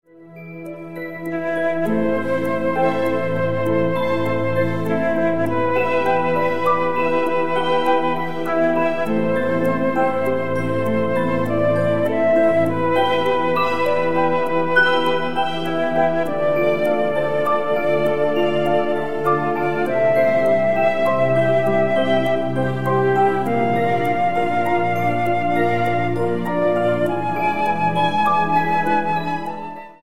100 BPM